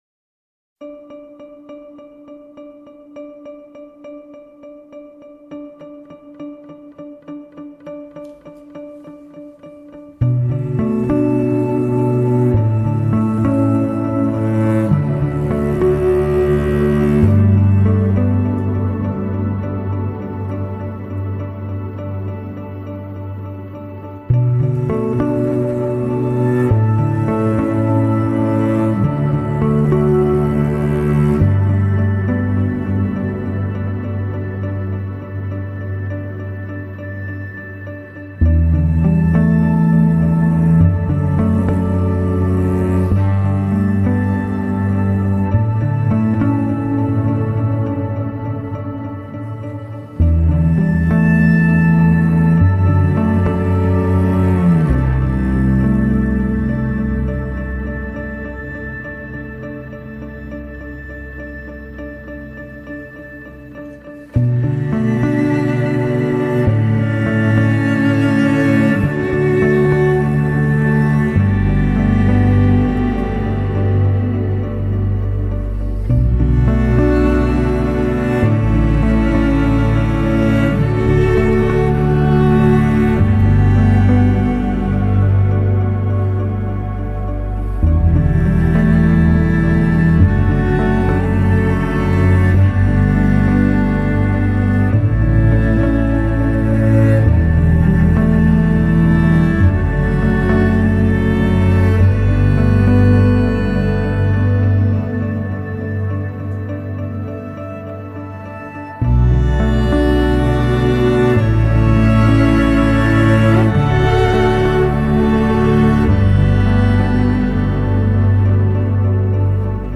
Soundtrack, Pop